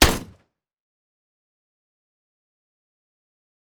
Assault Rifle Shot 3.wav